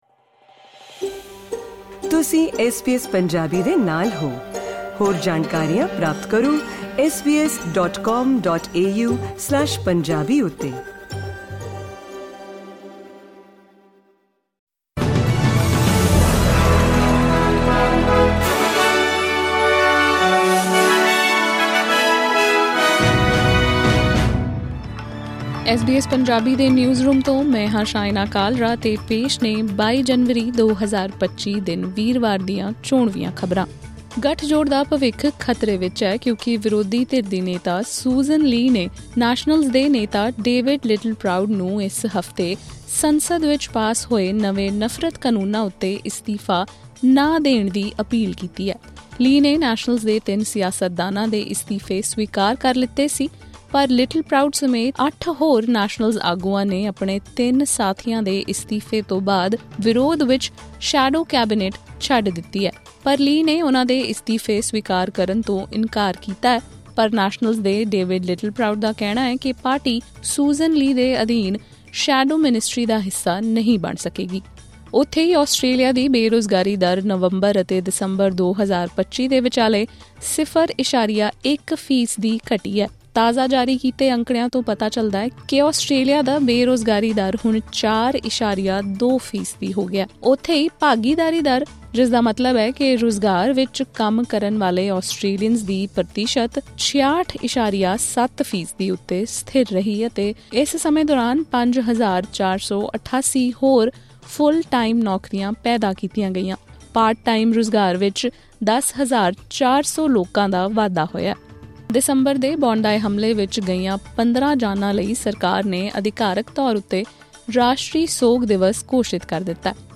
ਖ਼ਬਰਨਾਮਾ: ਗੱਠਜੋੜ ਵਿੱਚ ਆਈ ਦਰਾਰ, ਨਫ਼ਰਤ ਕਾਨੂੰਨਾਂ ਉੱਤੇ ਮੱਤਭੇਦ ਦੇ ਚਲਦੇ ਨੈਸ਼ਨਲਜ਼ ਪਾਰਟੀ ਦੇ 3 ਲੀਡਰਾਂ ਨੇ ਦਿੱਤਾ ਅਸਤੀਫ਼ਾ